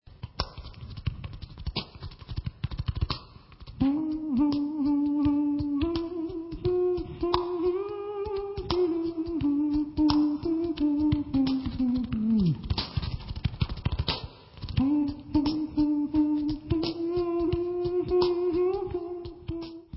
Album je zhuštěným audio záznamem jarního turné skupiny